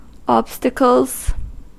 Ääntäminen
Ääntäminen US Haettu sana löytyi näillä lähdekielillä: englanti Käännöksiä ei löytynyt valitulle kohdekielelle. Obstacles on sanan obstacle monikko.